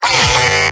Robot-filtered lines from MvM. This is an audio clip from the game Team Fortress 2 .
{{AudioTF2}} Category:Heavy Robot audio responses You cannot overwrite this file.
Heavy_mvm_painsharp04.mp3